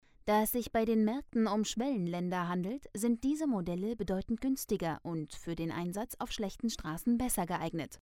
Seit dem bin ich professionelle Sprecherin für Kino/TV und Hörfunk Spots, XBox/PlayStation und PC Spiele, Synchron, Voice Over, Dokumentationen, Hörbücher und Hörspiele, Imagefilme/Industriefilme, E-Learning, Telefonansagen, Navigationssysteme, u.v.m. Meine Stimmlage ist Sopran und mein Stimmalter fällt in die Kategorie „Jugendliche bis junge Erwachsene“.
Meine Stimmfarbe lässt sich mit den Worten „jung, dynamisch, frisch, frech, facettenreich, jugendlich, mittelkräftig, sanft, seriös, sinnlich, warm und weich“ beschreiben.
Sprecherin Deutsch & Türkisch akzentfrei.
Sprechprobe: Industrie (Muttersprache):